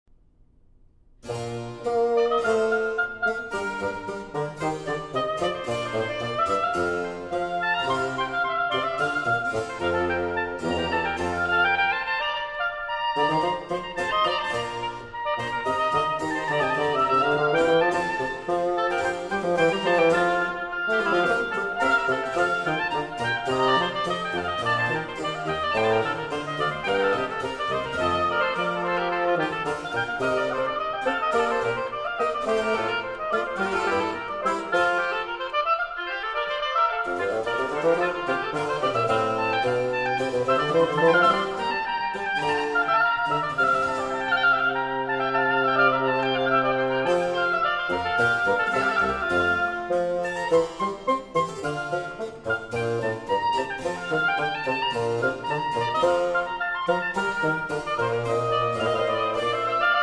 Oboe
Bassoon
Harpsichord
from Trio Sonata in B-Flat Major